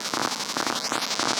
Index of /musicradar/rhythmic-inspiration-samples/170bpm
RI_ArpegiFex_170-05.wav